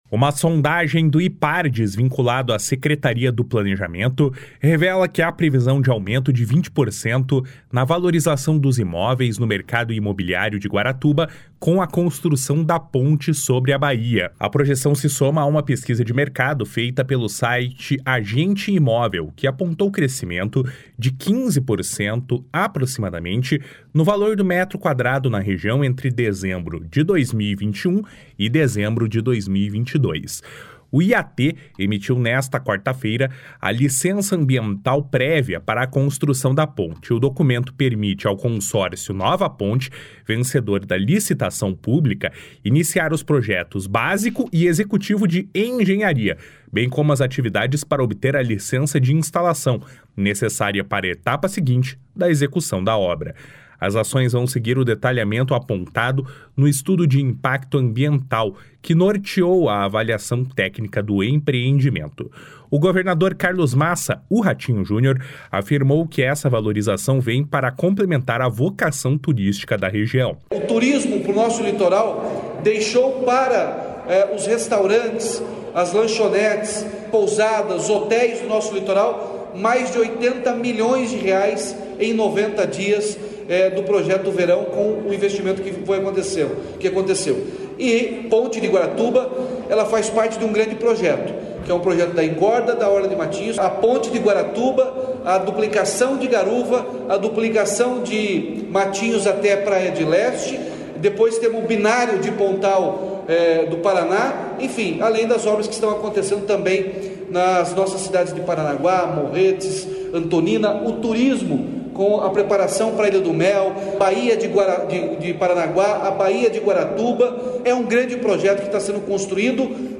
// SONORA RATINHO JUNIOR //
// SONORA ROBERTO JUSTUS //